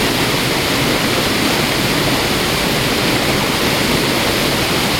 waterfall.ogg